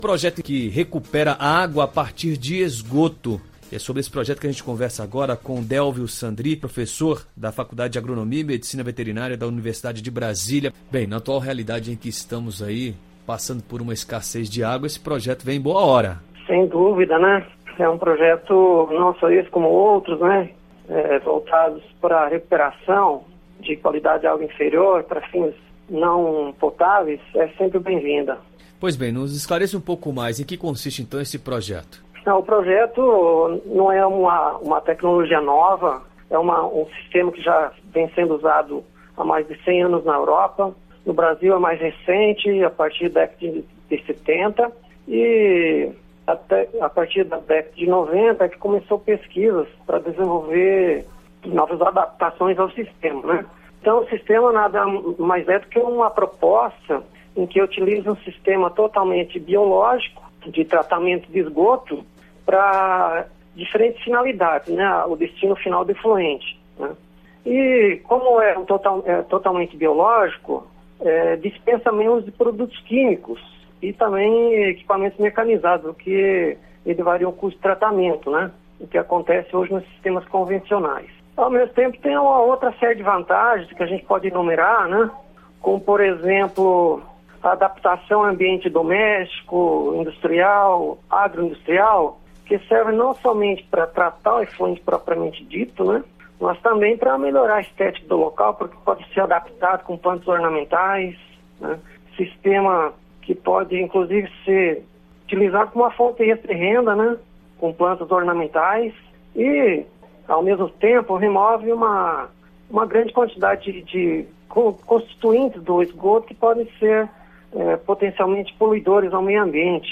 Entrevista: Entenda como a água de esgoto tratada pode ser reaproveitada